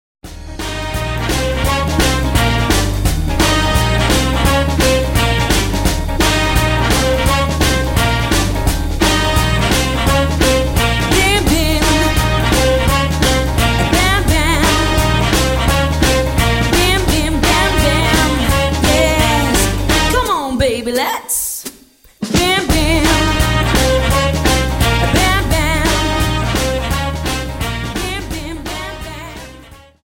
Dance: Jive Song